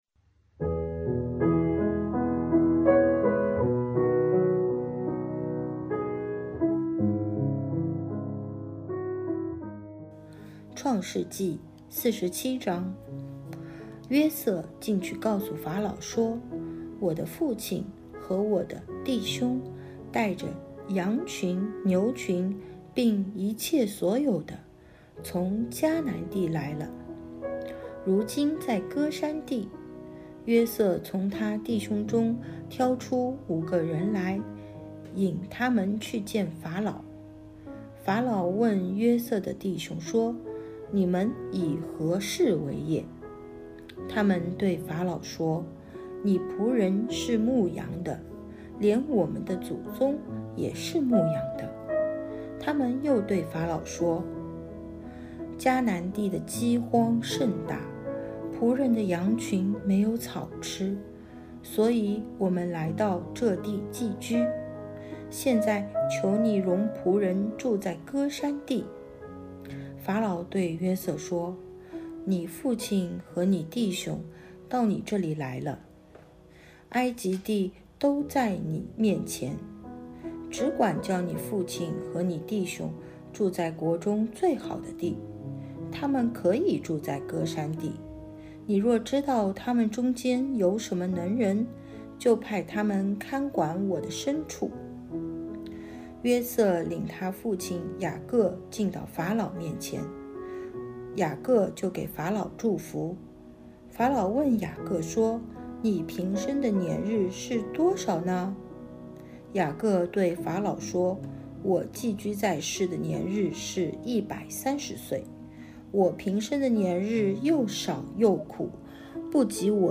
读经马拉松 | 创世记47章(国语)